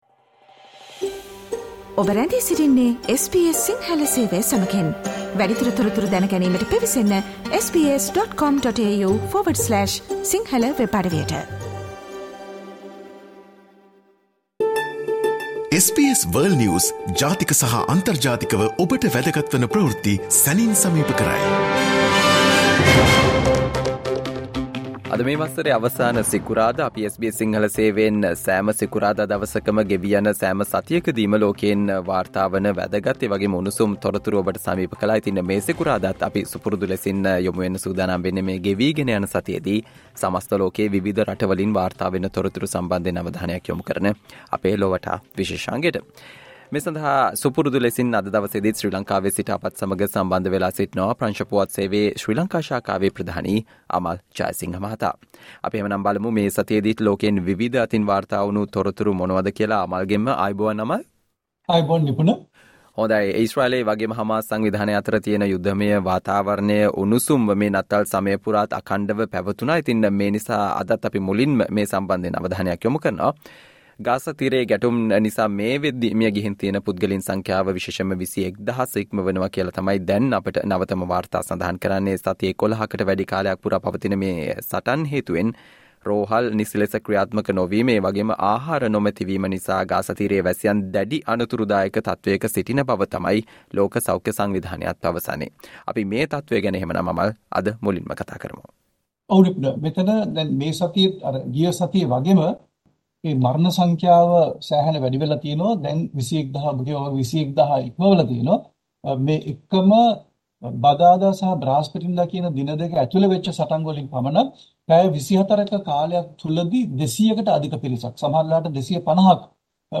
US Secretary of State on a quick visit to Mexico while Gaza deaths soar : World News Wrap